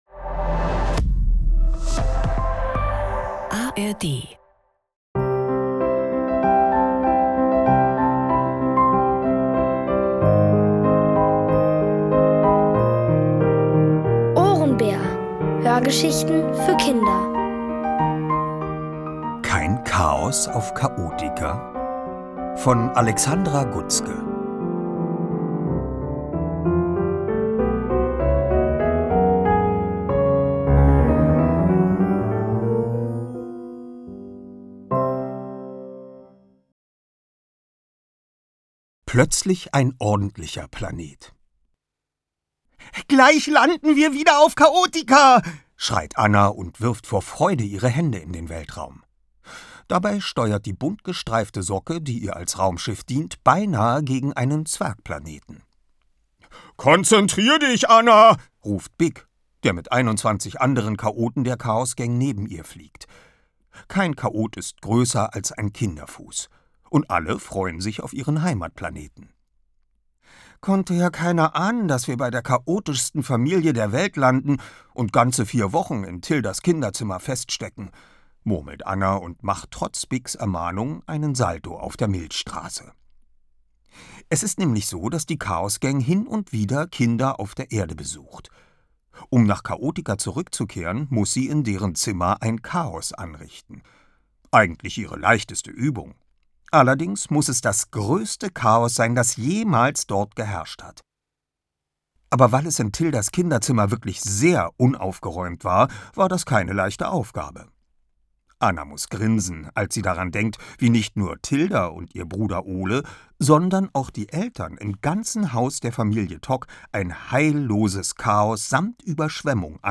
Es liest: Oliver Rohrbeck.